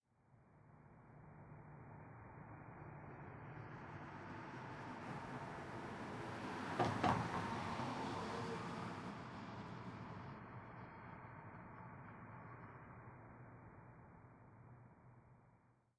1Shot Vehicle Passby with Tire Bumps ST450 01_ambiX.wav